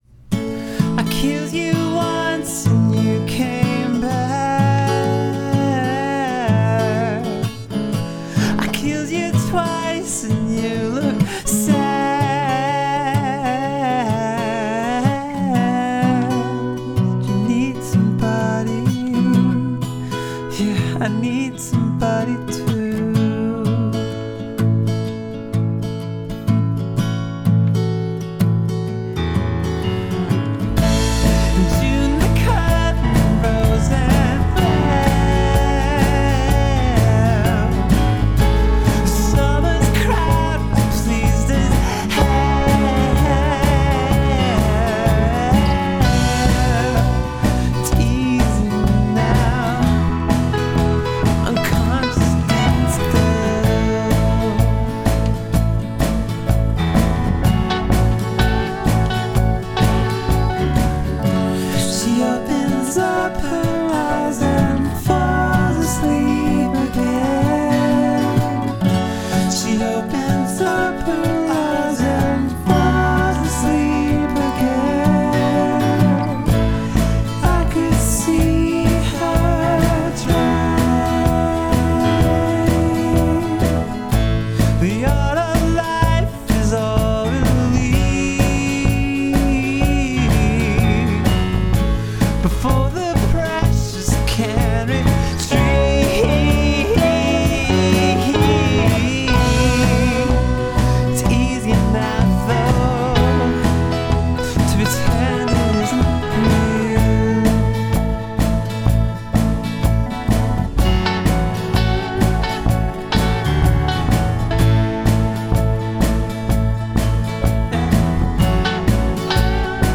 I recorded this in my basement, it'll change before it's READY... whatever that means.